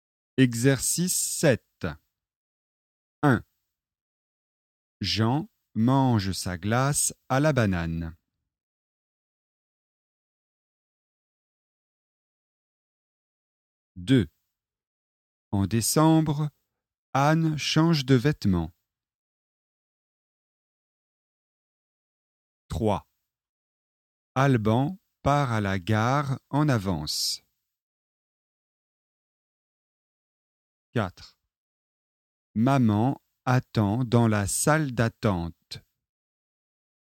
Le son [ɑ̃] est une voyelle nasale.
🔷 Exercice 7 : lisez les phrases suivantes :